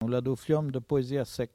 Maraîchin
Patois - archives
Catégorie Locution